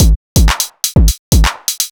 Brought Beat 125.wav